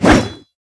troll_commander_siwsh.wav